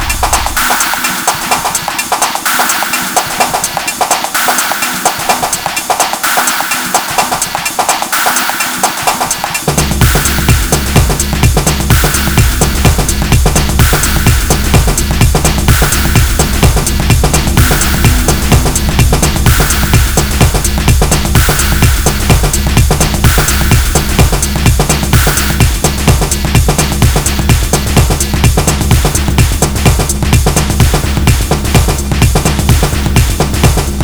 DT (alphabase drum samples) & TR-8s. Token Detroit-Deutschland techno jam.